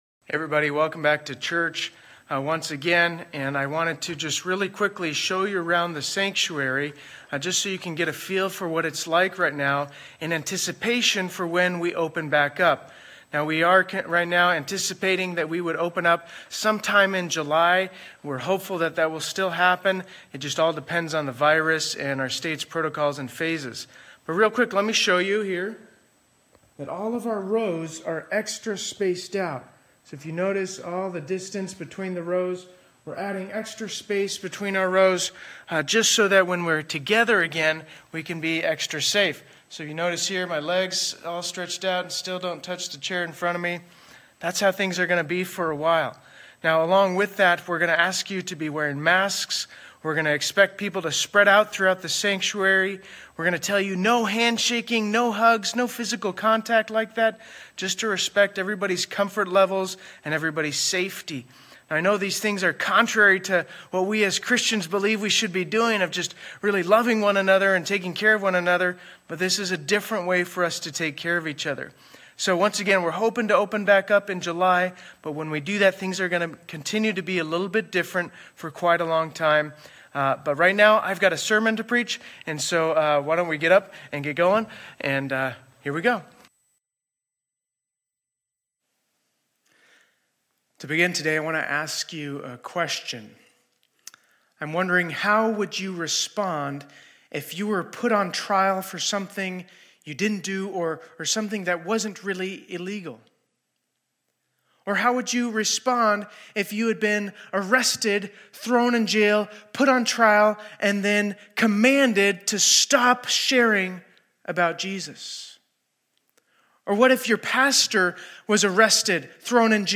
2020-06-14 Sunday Service